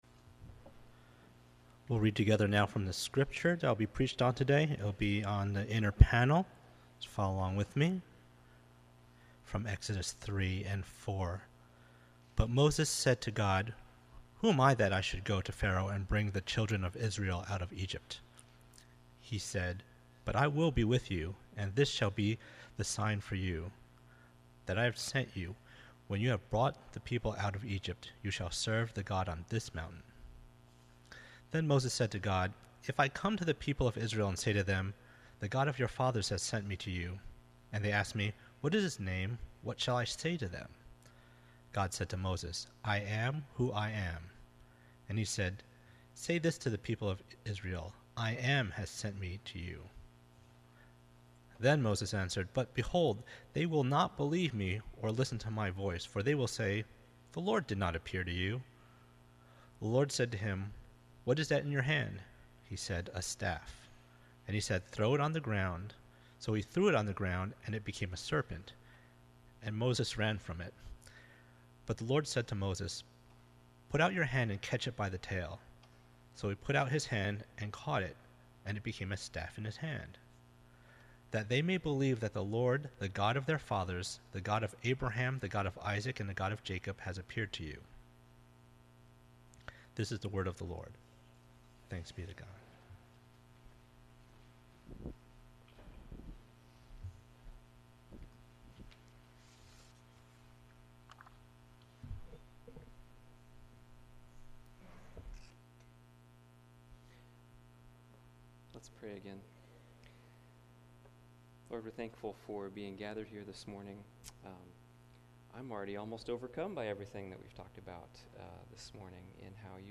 I AM has sent you Preacher